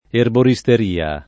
[ erbori S ter & a ]